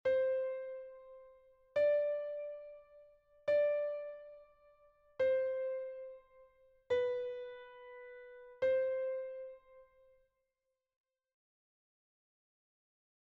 note recognition exercise 1
1_note_recognition_clave_sol_D_agudo_6_notas.mp3